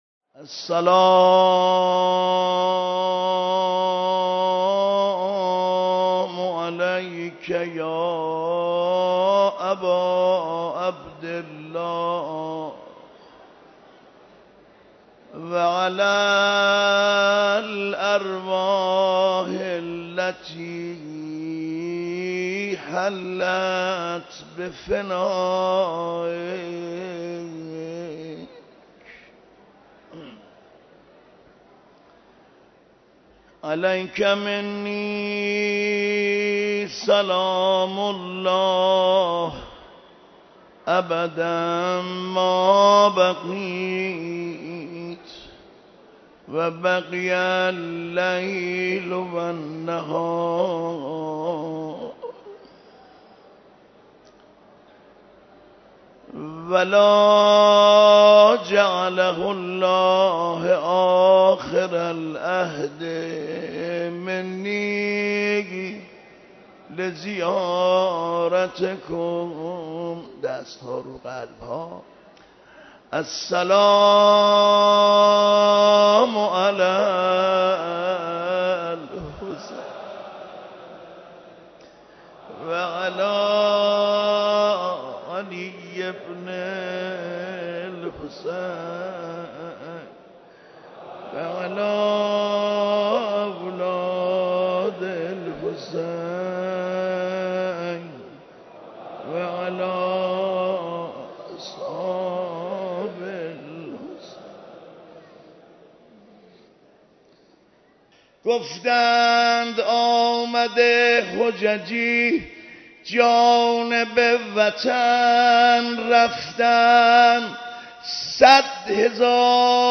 مراسم عزاداری شام غریبان
مراسم عزاداری شام غریبان برگزار شد
مداحی